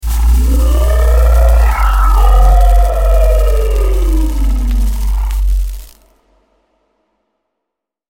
Глухие шаги, рычание, эхо пещер – все для погружения в атмосферу мифа.
Звук: грозный рык циклопа